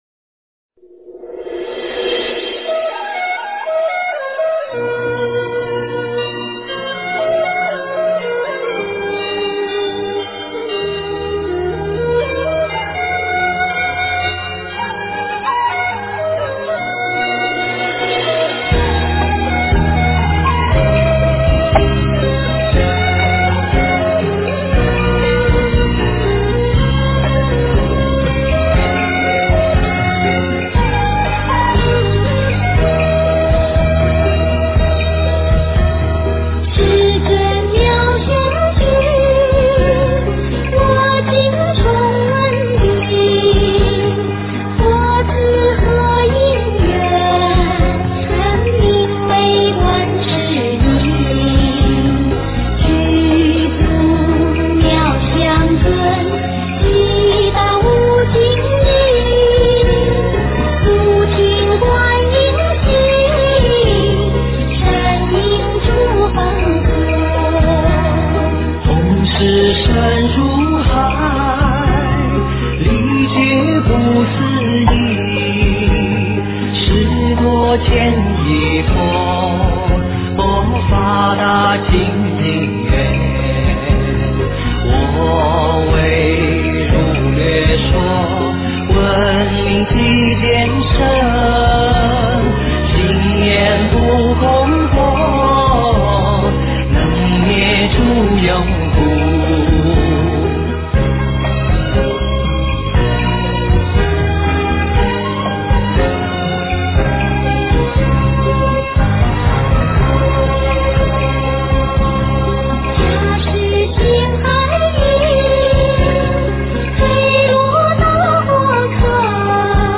观音普门品 诵经 观音普门品--佛音 点我： 标签: 佛音 诵经 佛教音乐 返回列表 上一篇： 吉祥经 下一篇： 大般若波罗蜜多经第490卷 相关文章 日出--瑜伽曲 日出--瑜伽曲...